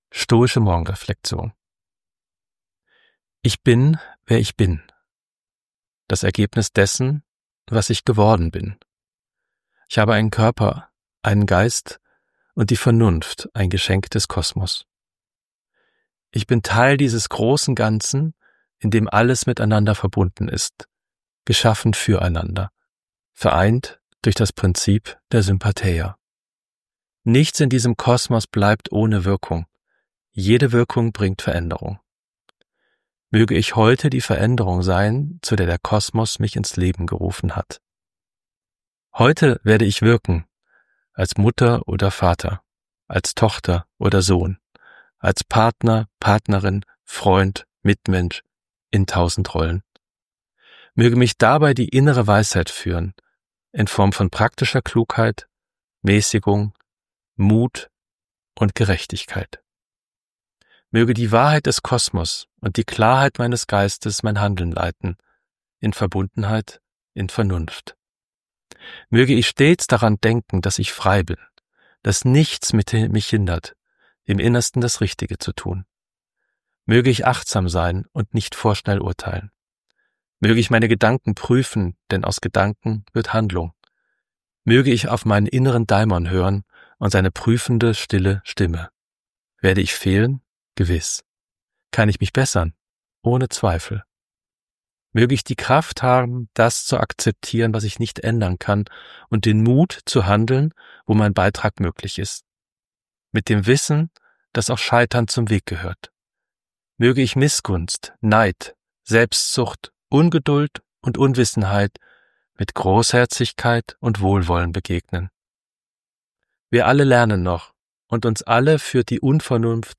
Eine Audioversion der Reflexion ohne An- und Abmoderation findet sich